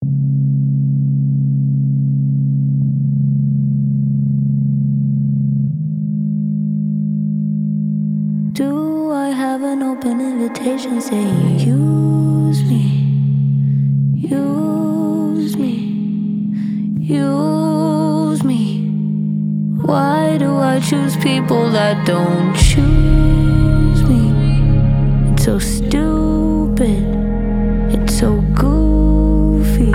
Жанр: Поп музыка
Pop